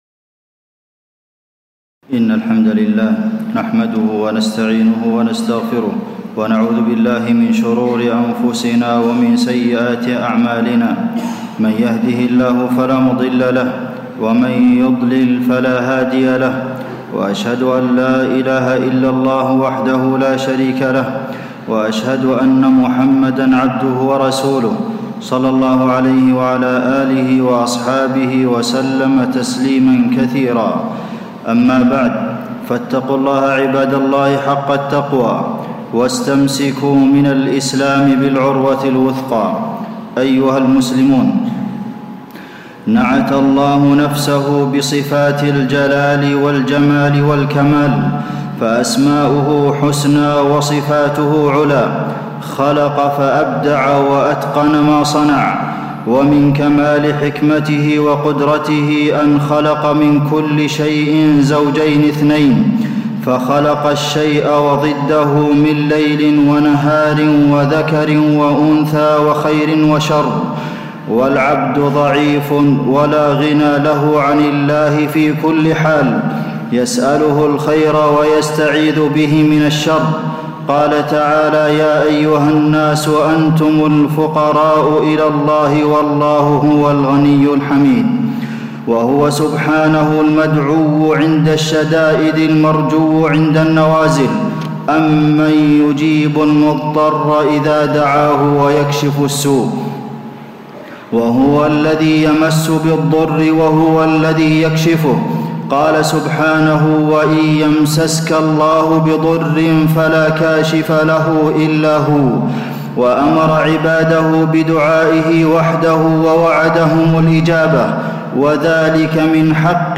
تاريخ النشر ١ صفر ١٤٣٧ هـ المكان: المسجد النبوي الشيخ: فضيلة الشيخ د. عبدالمحسن بن محمد القاسم فضيلة الشيخ د. عبدالمحسن بن محمد القاسم الاستعاذة في الكتاب والسنة